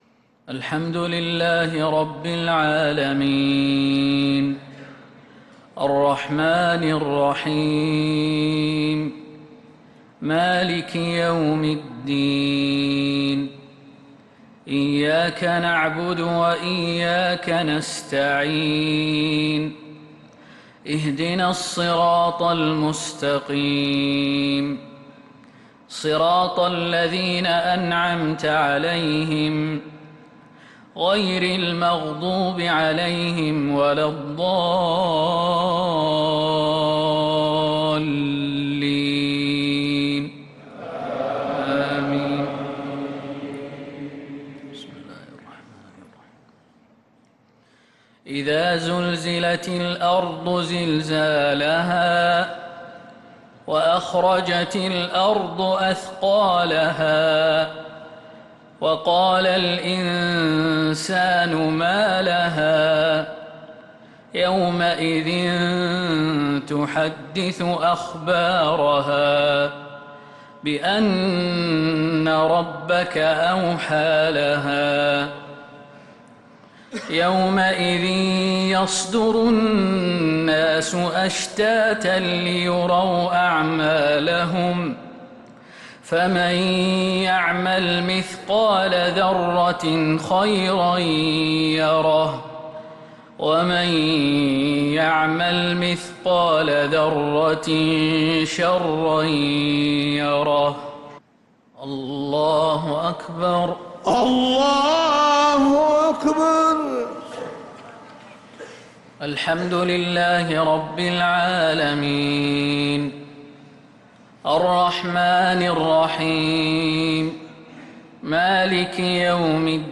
صلاة المغرب للقارئ خالد المهنا 11 ذو القعدة 1445 هـ